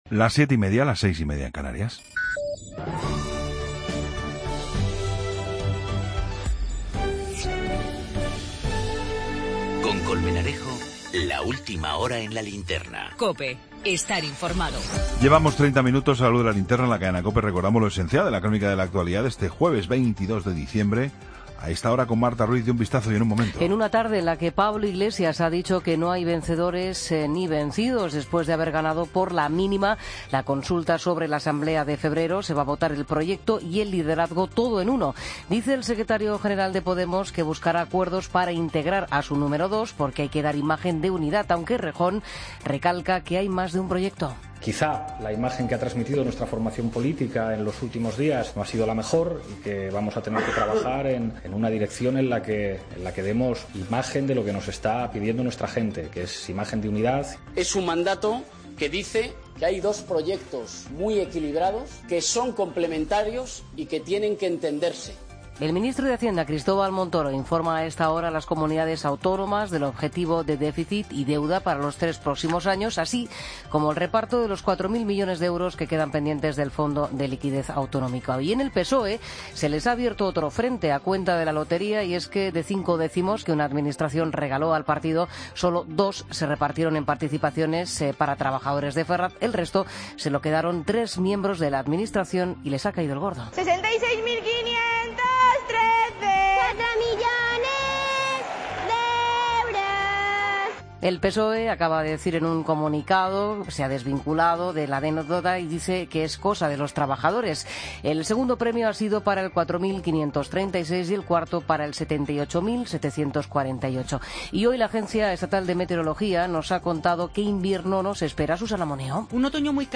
Ronda de corresponsales. Sección de Emprendedores.